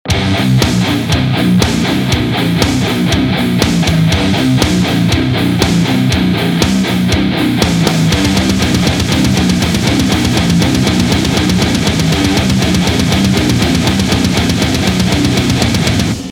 24.75 ��������� - 14-70 GHS �������� ������ ��� ��������, ����� drop A ��� G#. ��������� ������ �� �����) ������ - ���� ���� 81��.